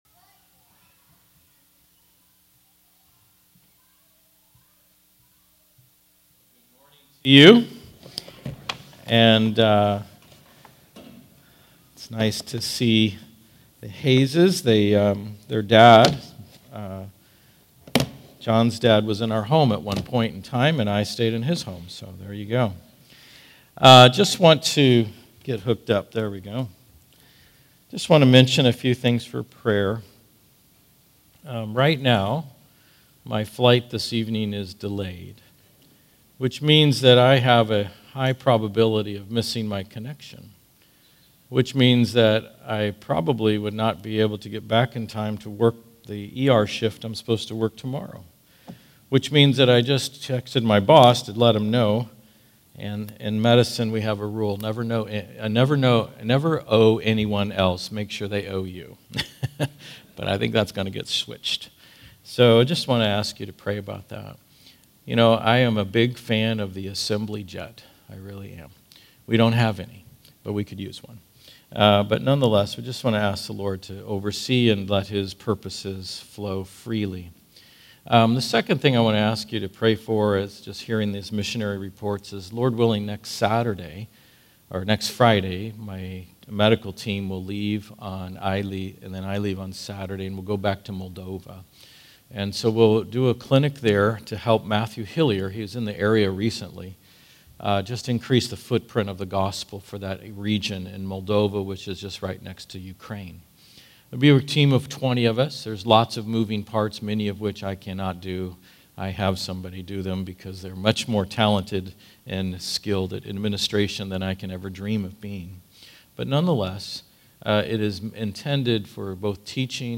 All Sermons Fall Conference 2025